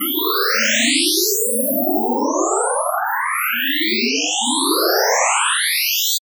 Coagula is a bitmap to sound converter.